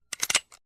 Sound Buttons: Sound Buttons View : Gunclick
Gun-click-Sound-effect.mp3